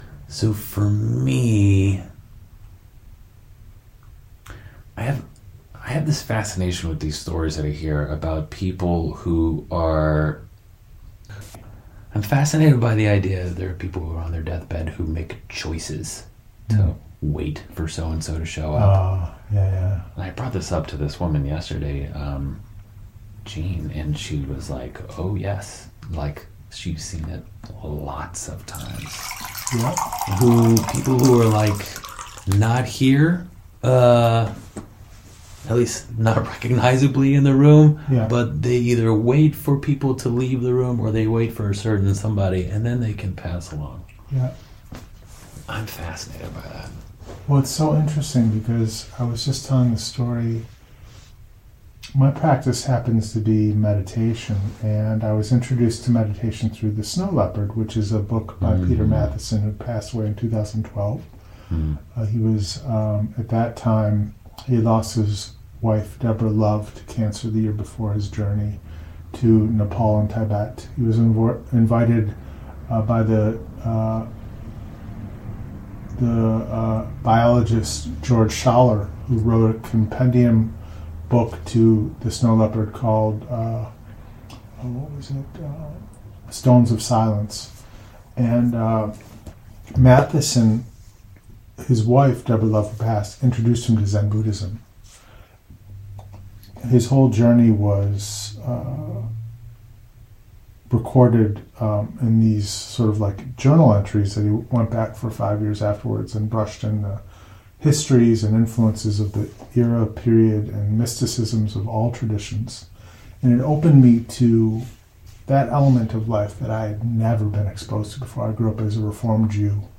Part 2 Three Words On Your Tombstone; Three Counselors Talk On Death, Meditation, Meaning, and Suffering